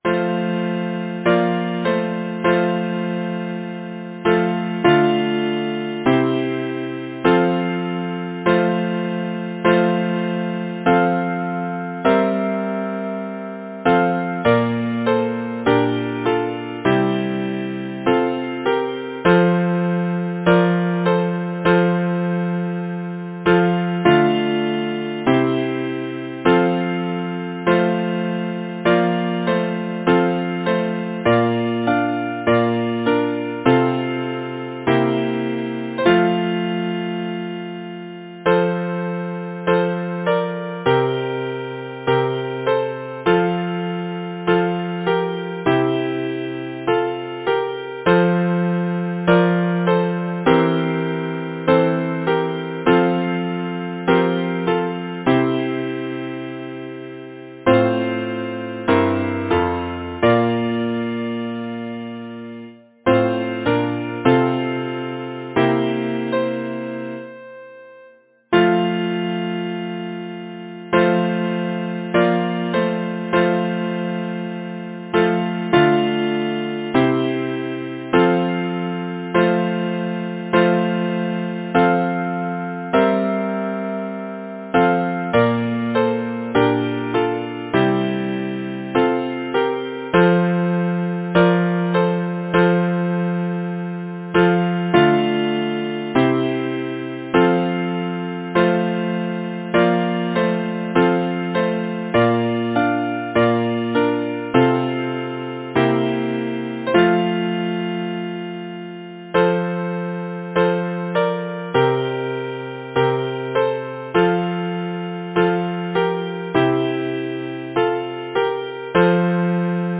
Title: All Round is Hushed Composer: Philip P. Bliss Lyricist: Number of voices: 4vv Voicing: SATB Genre: Secular, Partsong, Glee
Language: English Instruments: A cappella